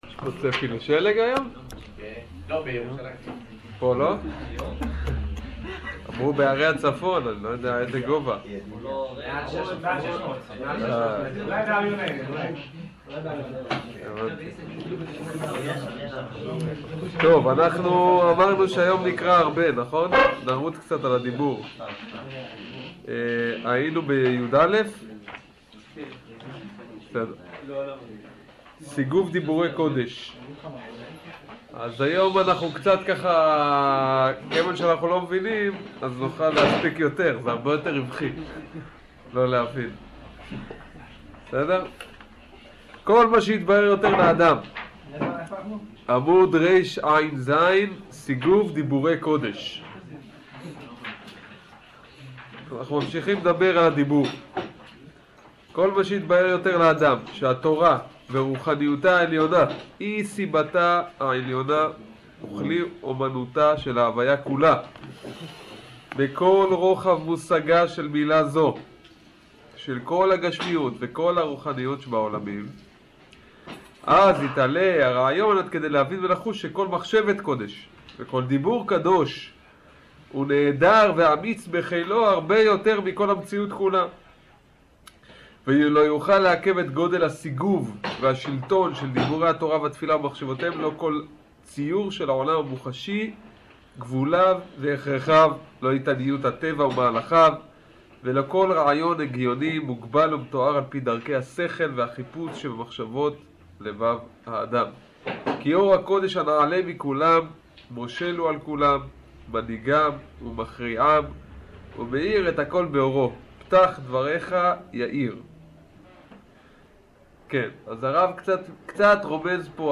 שיעור פסקאות י"א י"ג